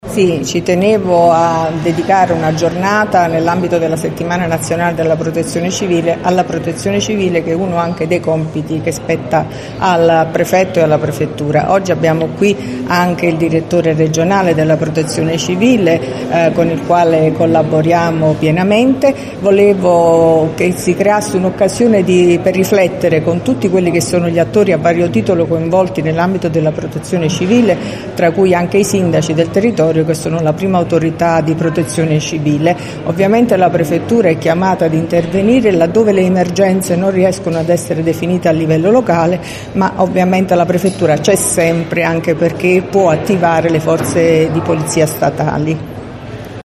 LATINA – Si è aperta con un convegno organizzato dalla Prefettura di Latina presso la Sala Conferenze della Facoltà di Economia del Polo Pontino della Sapienza Università di Roma, dal titolo “Prevenzione del rischio e buone pratiche di protezione civile”, la Settimana Nazionale della Protezione Civile che si svilupperà con una serie di appuntamenti in programma fino al 12 ottobre.